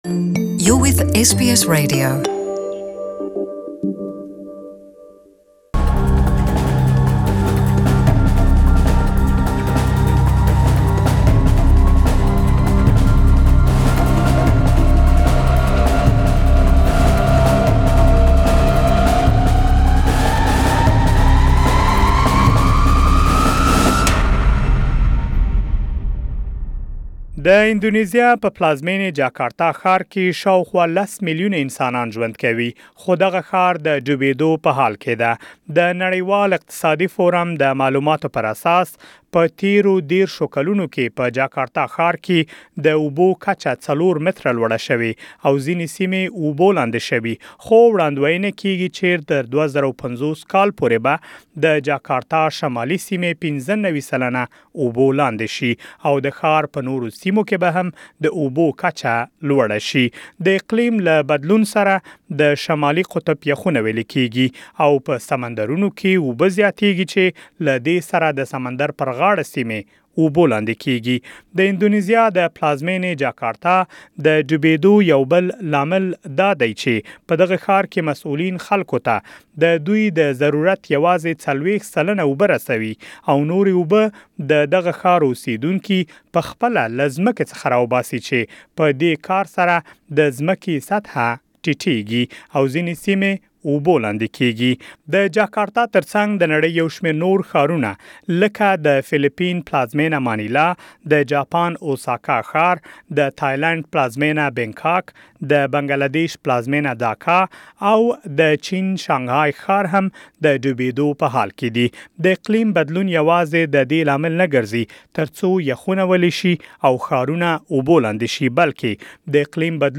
However, by the year 2050, most of the city will be under water. For more details, Please listen to the full report in Pashto.